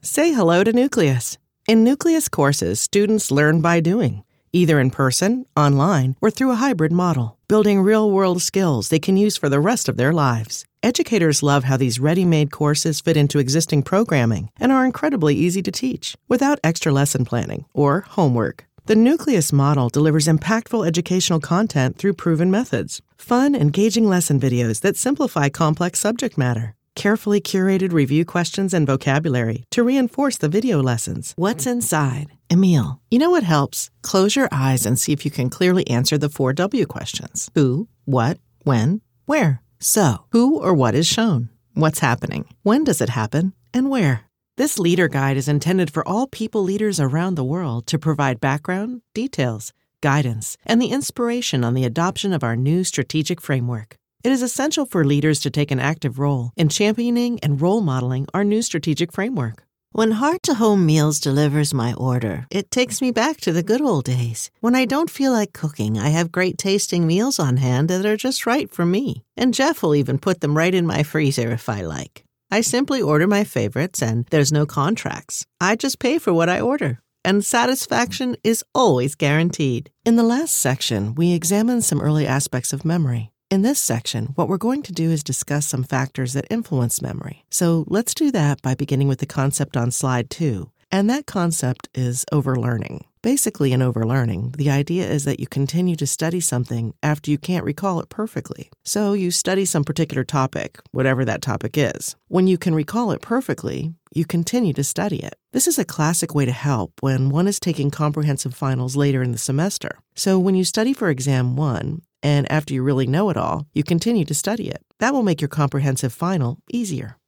Female Voice Over, Dan Wachs Talent Agency.
Agile, Passionate, Mama Bear
eLearning